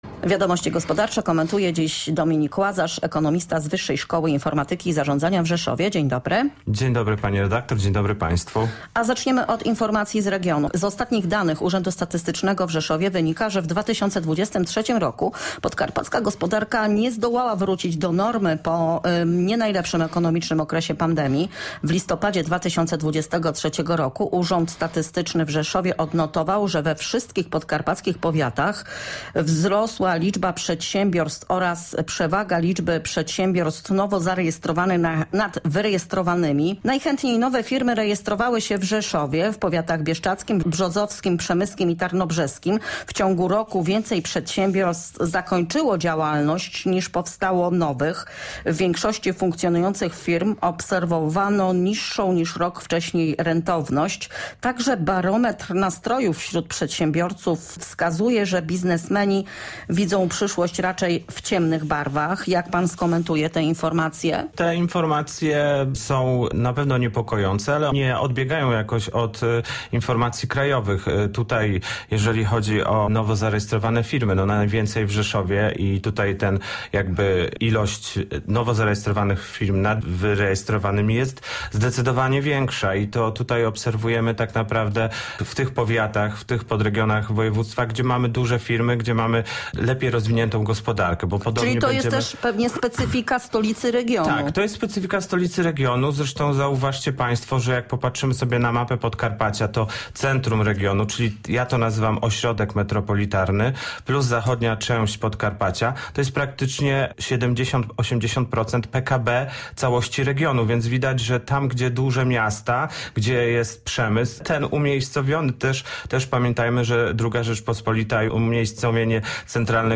Wiadomości gospodarcze – rozmowa